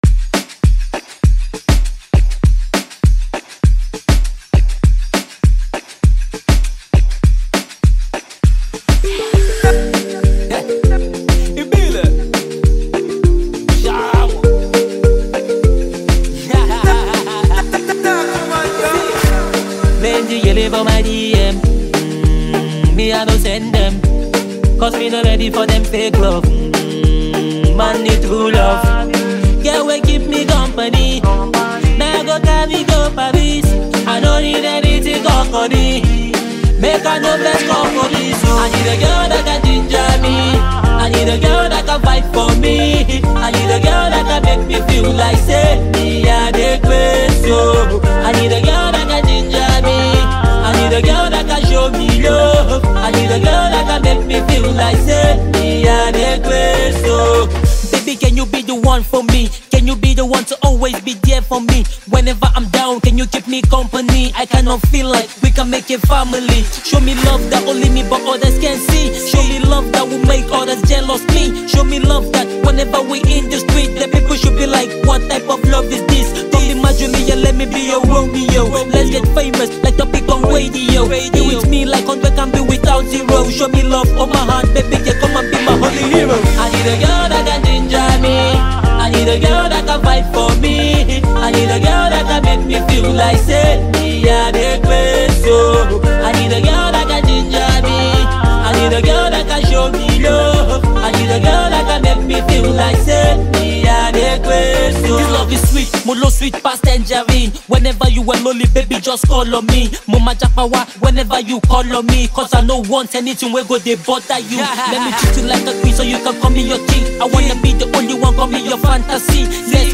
Known for his smooth vocals and infectious melodies